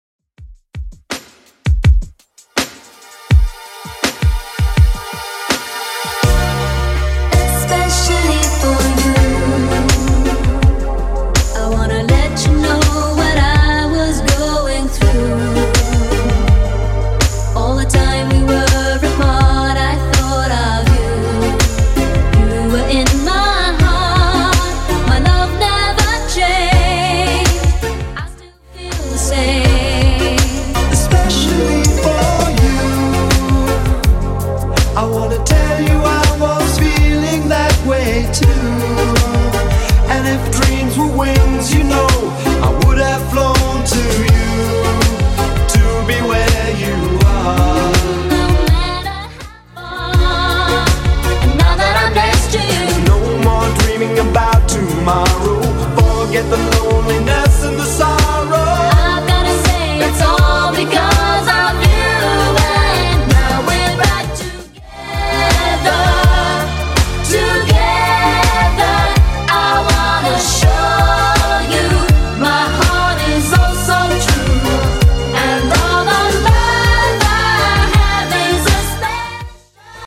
Genre: 70's Version: Clean BPM: 98